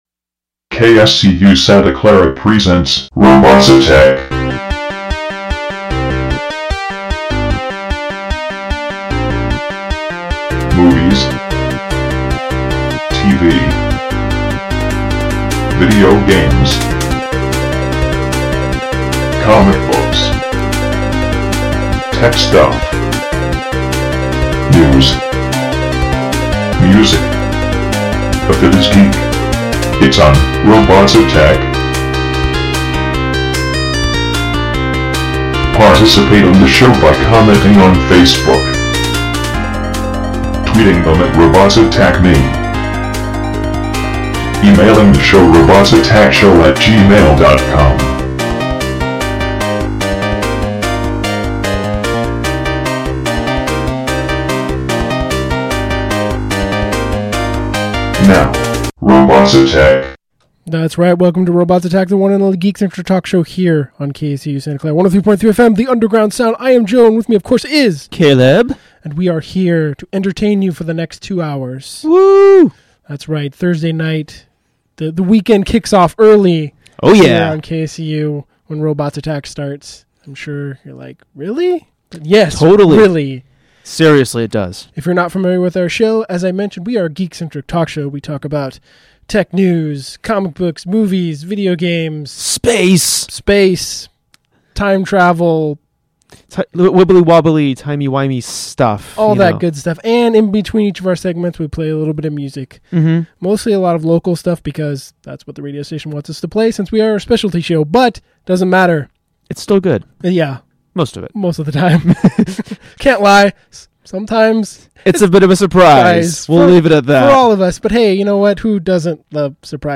Listen live Thursdays from 7pm to 9pm on 103.3 FM KSCU Santa Clara